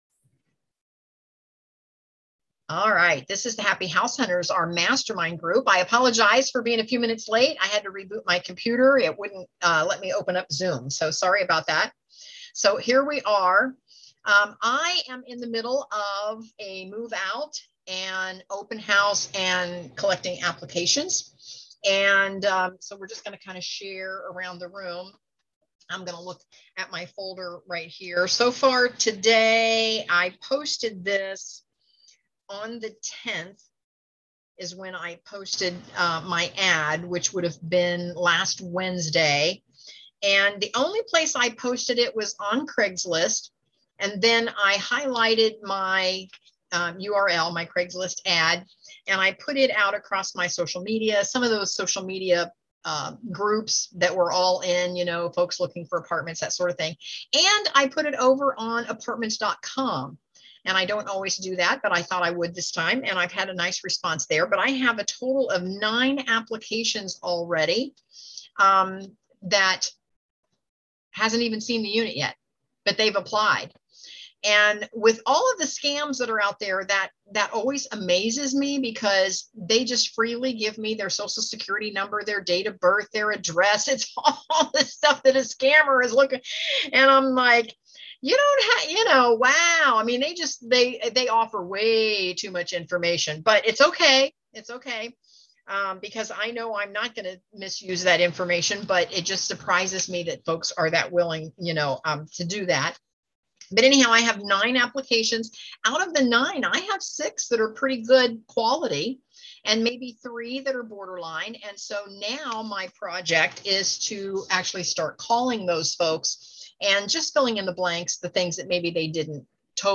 We hear from our members telling us about their most recent frustrations and wins with buy and hold investments.